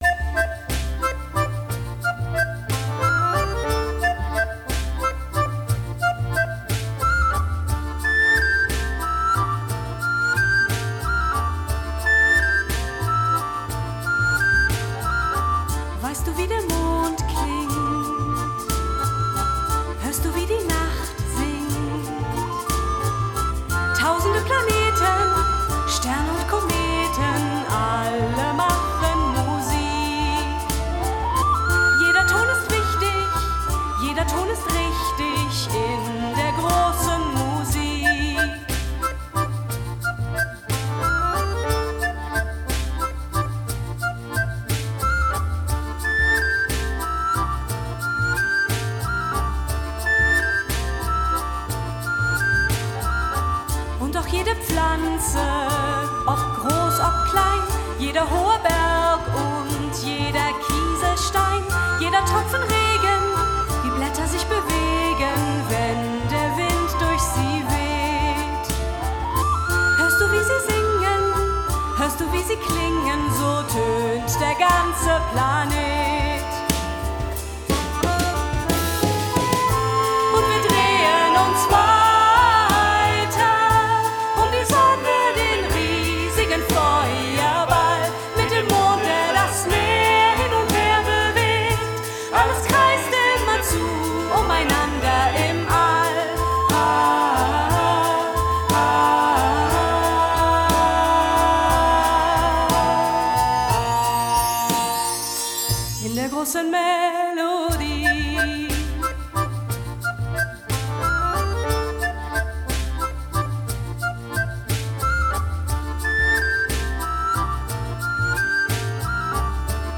Oboe, Blockflöte
Akkordeon, Gesang
Kontrabass
Schlagzeug / Percussion
Titelmelodie aus unserem Kinderkonzert